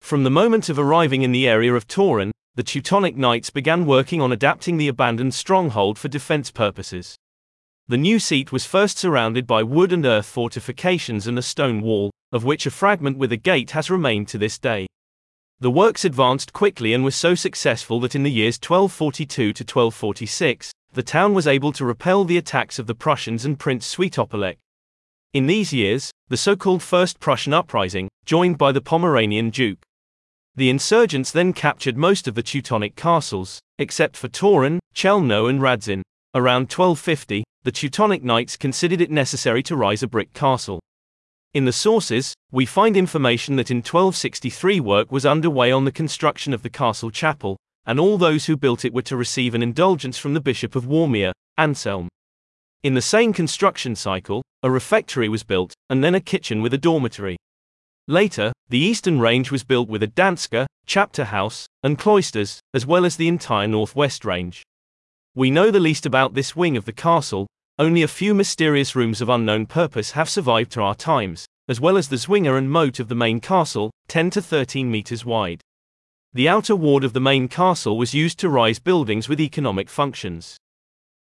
audioprzewodnik-16-history-of-the-castle-eng.mp3